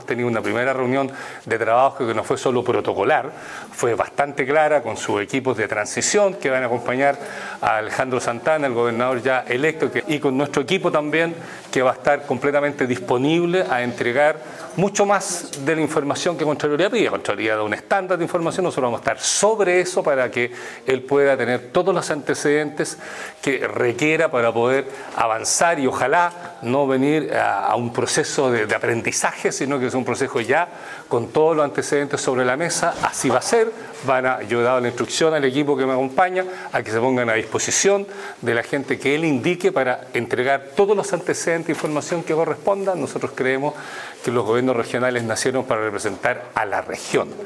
El Gobernador Vallespín destacó la importancia de este encuentro, subrayando que la reunión no fue meramente formal, sino que se trató de un encuentro claro y orientado a proporcionar a los equipos del Gobernador Electo toda la información relevante.